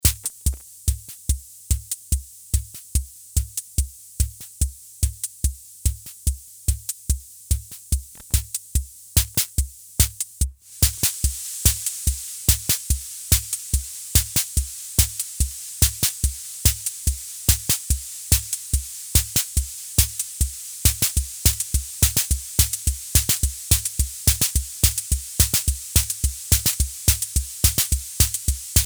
Basic Italian analog drum machine with patterns presets styles and no sync option.
congo bongo2 snare cymbal
bongo1 claves maracas kick drum